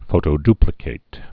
(fōtō-dplĭ-kāt, -dy-)